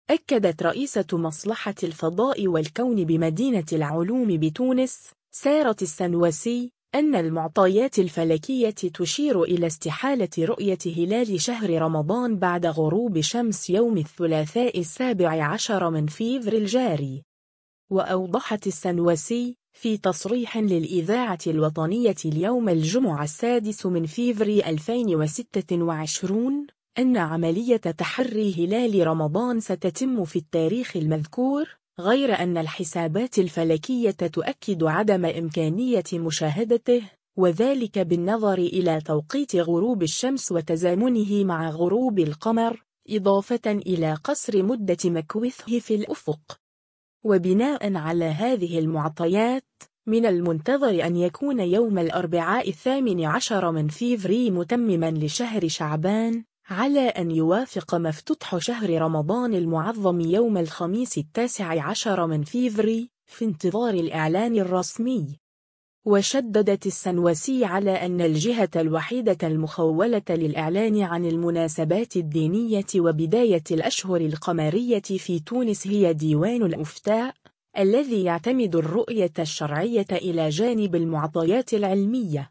في تصريح للإذاعة الوطنية